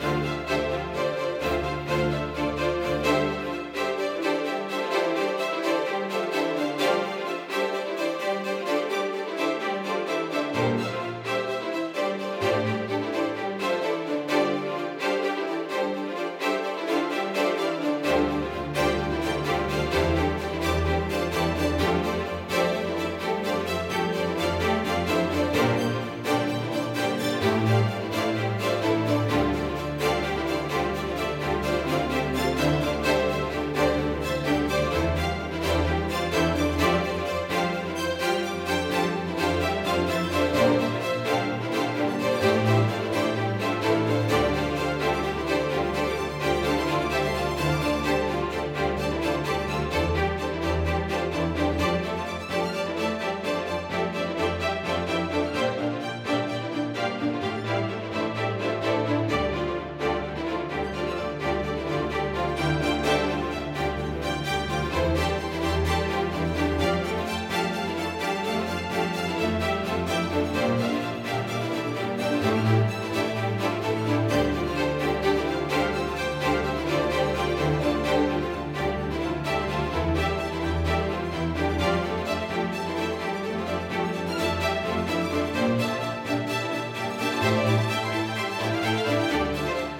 ジャンル(スタイル) TECHNO